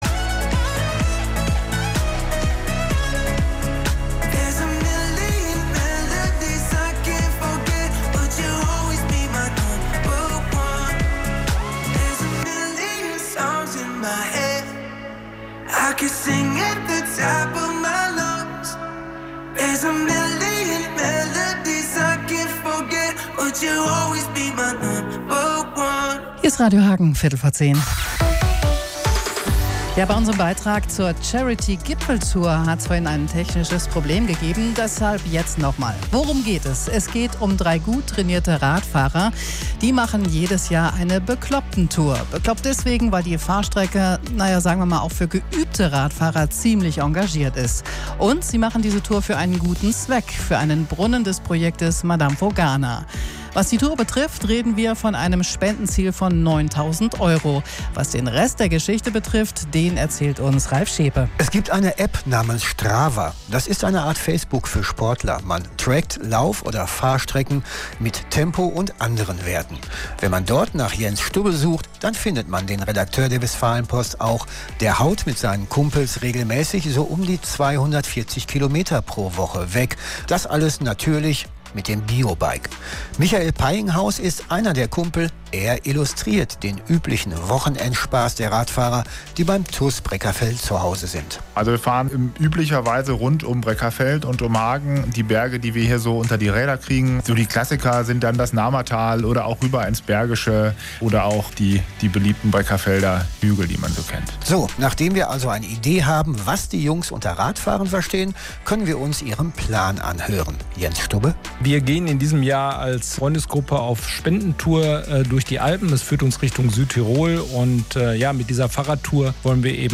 Radiobericht